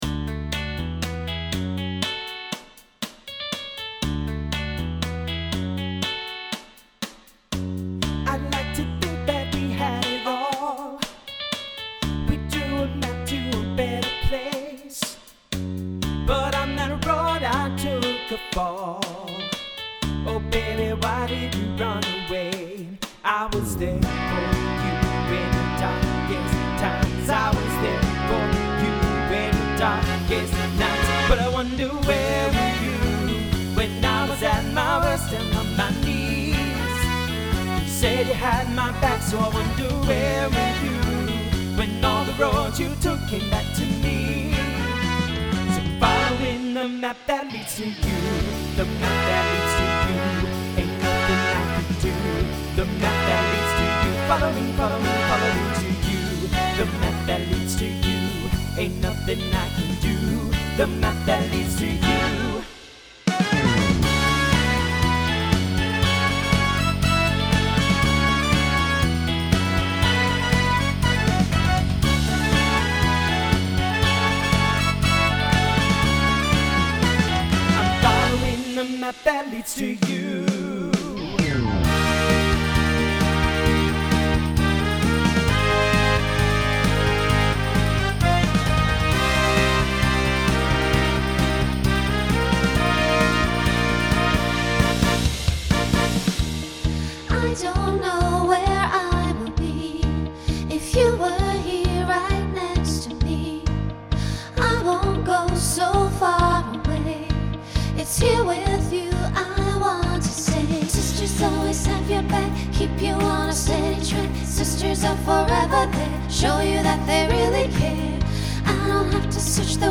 TTB/SSA/SATB
Pop/Dance , Rock
Transition Voicing Mixed